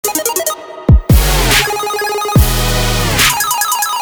Electronica
на смс
Dubstep